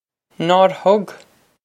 Pronunciation for how to say
Naw-r hug?
This is an approximate phonetic pronunciation of the phrase.